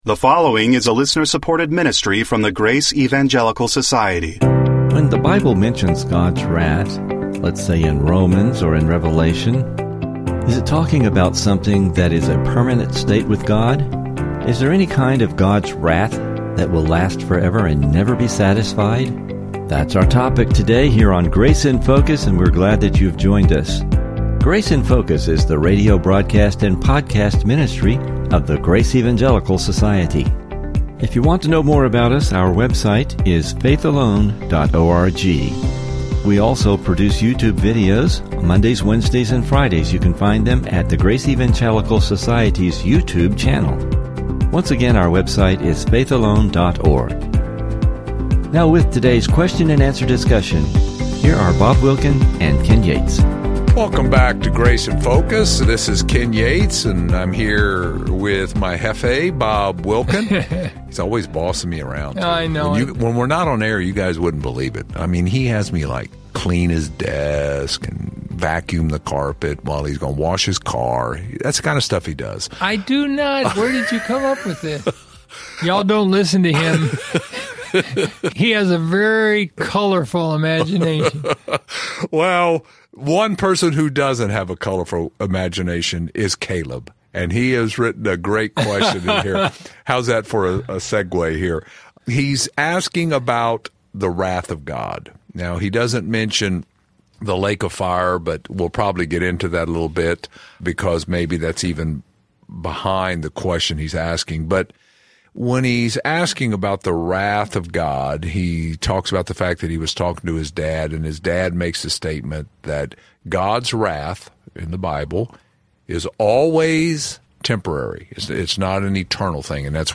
Welcome to Grace in Focus radio.